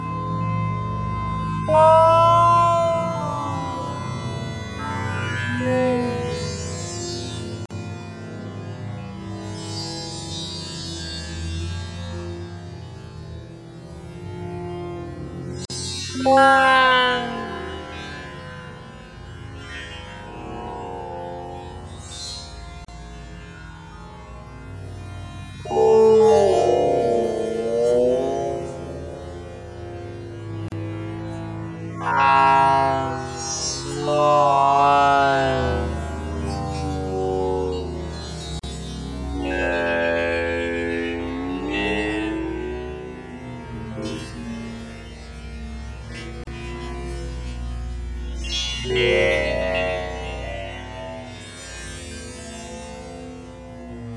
The audio was grabbed from The Doctor Who special of 2012.